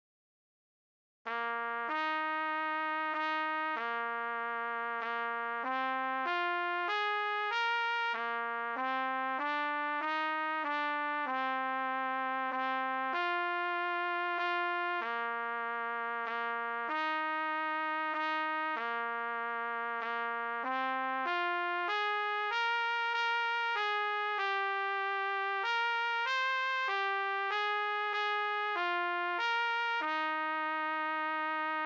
D=Counter-melody/Harmony/Bass Part-for intermediate to experienced players